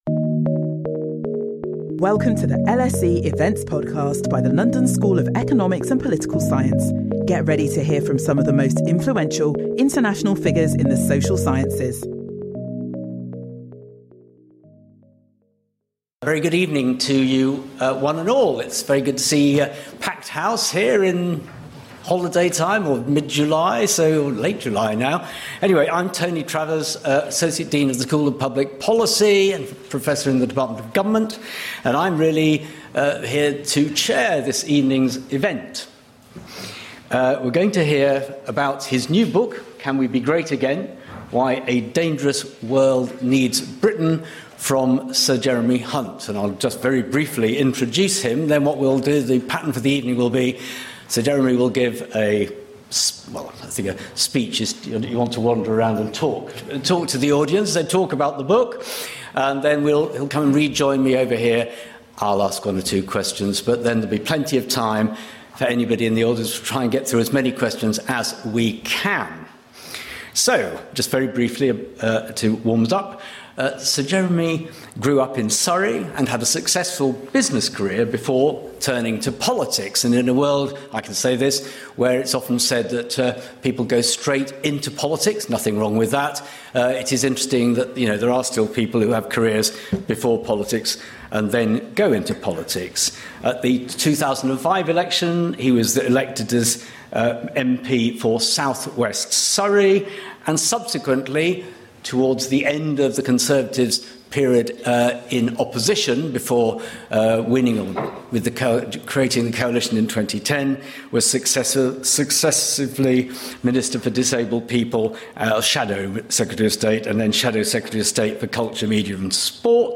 Join us for this talk by Jeremy Hunt in which he will talk about his new book, Can We Be Great Again?: Why a Dangerous World Needs Britain.